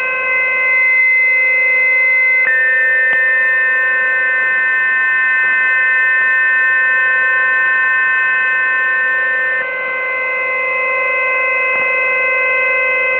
CIS 3x PSK
RUS-INTEL VFT (2 or 3 CHANNEL PSK 64 Bd , BROADCAST) AUDIO SAMPLES RUS-INTEL PSK broadcast-mode in standby condition RUS-INTEL PSK broadcast-mode in idle condition RUS-INTEL PSK broadcast-mode in tfc. condition back to PSK-systems page
CIS_3xPSK_stby.WAV